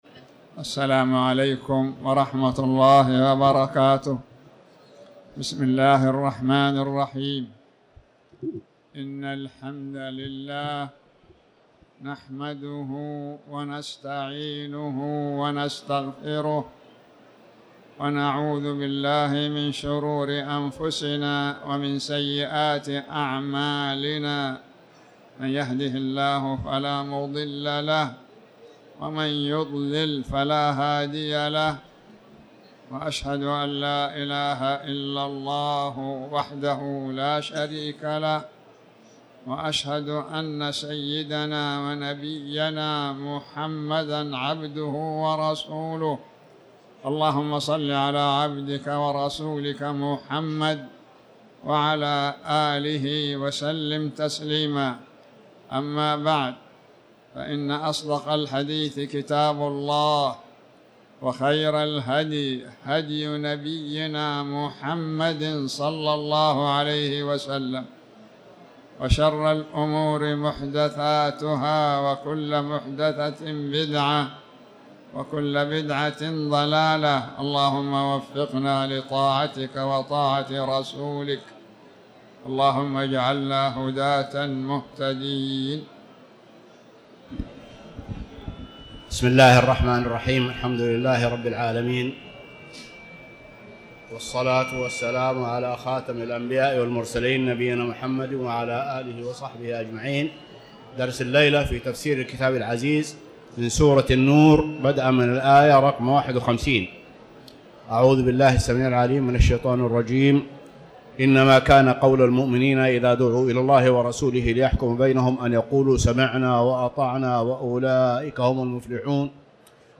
تاريخ النشر ٢٢ جمادى الأولى ١٤٤٠ هـ المكان: المسجد الحرام الشيخ